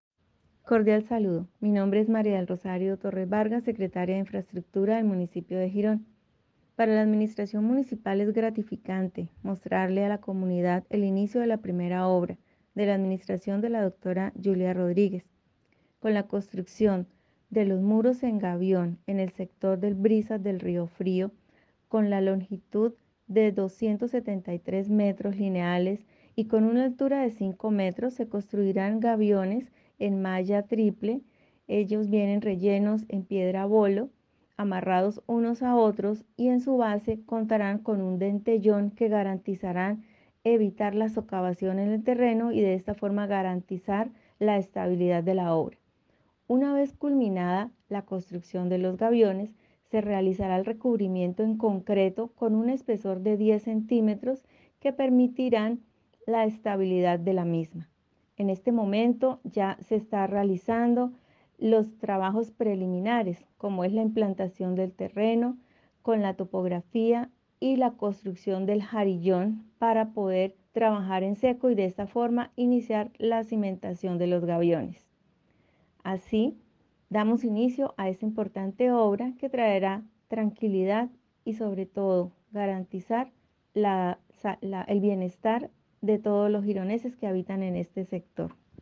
MARÍA DEL ROSARIO, Secretaria de Infraestructura.mp3